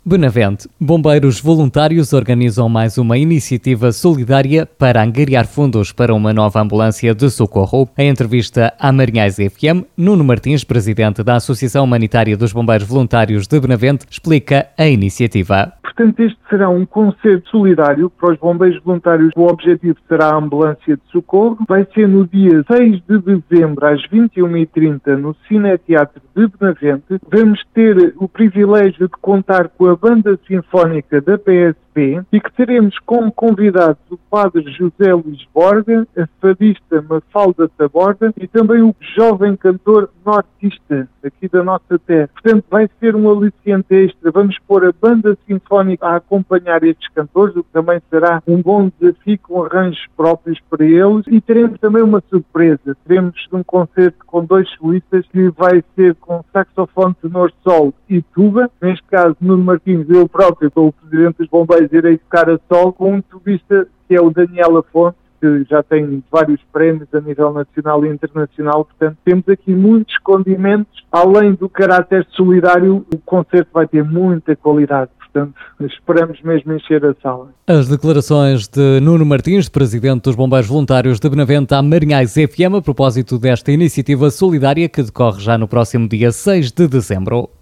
Em entrevista à Rádio Marinhais